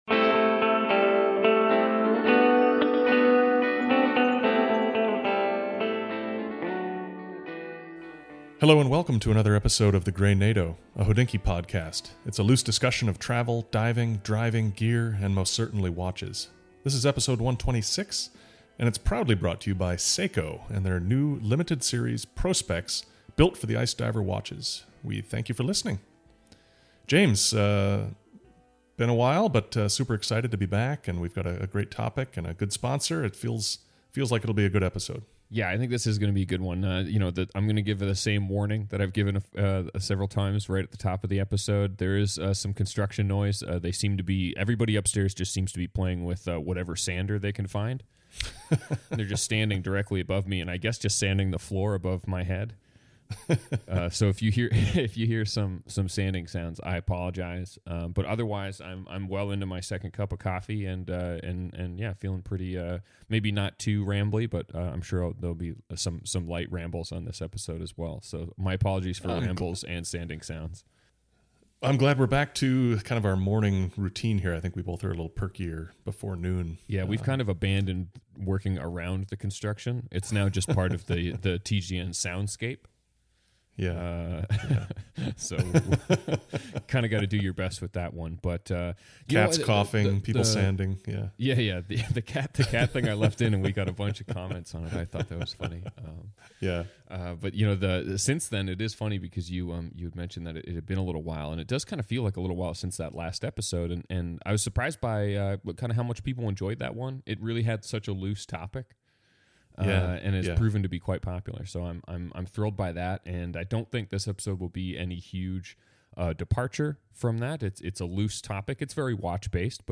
It's a loose discussion of travel, diving, driving, gear, and most certainly watches.